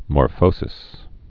(môr-fōsĭs)